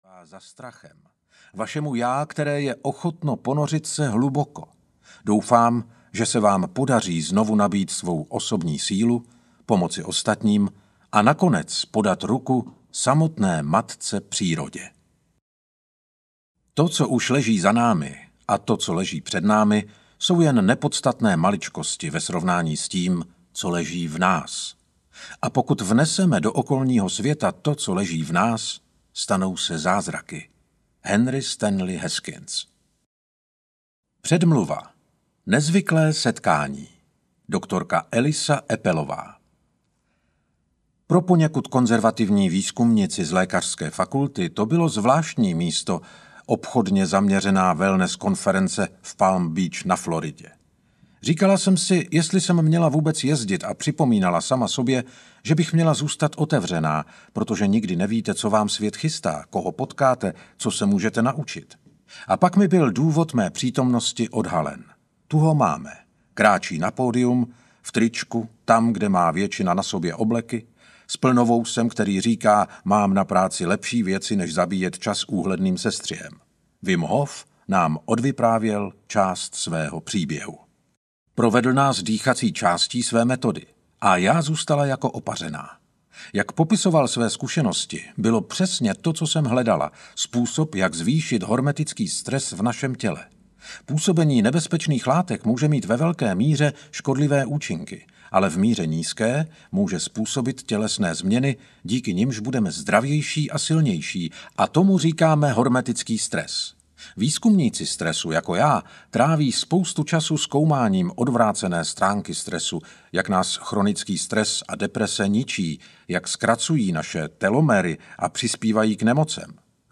Ukázka z knihy
wim-hof-ledovy-muz-audiokniha